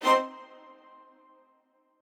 strings5_29.ogg